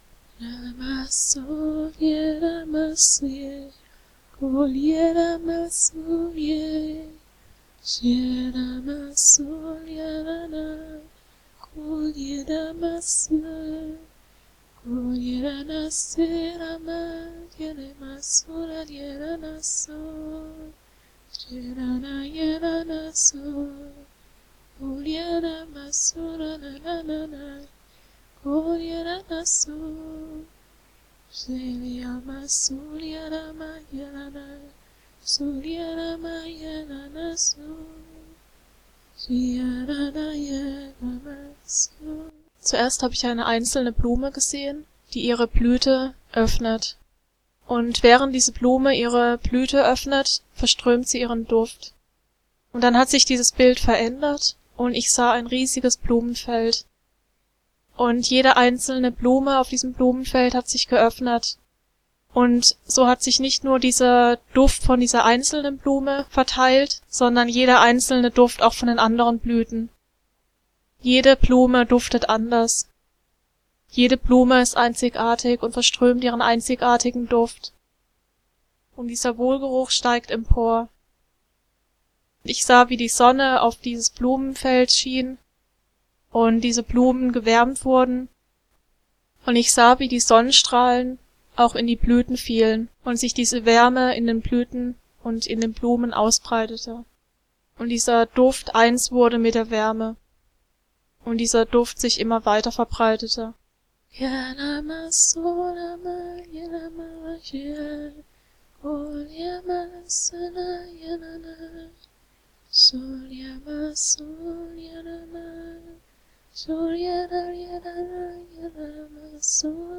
Du bist Gottes wunderschöne Blume! (Sprachengesang mit prophetischer Botschaft)
Spontane Aufnahme vom 07.10.2012.